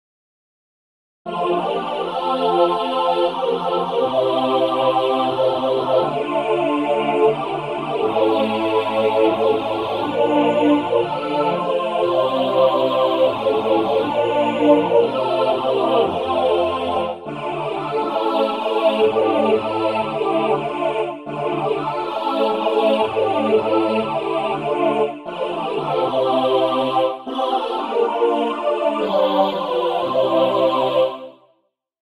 Sacré ; Chœur Caractère de la pièce : adorant
SATB (4 voix mixtes )
Tonalité : fa (centré autour de)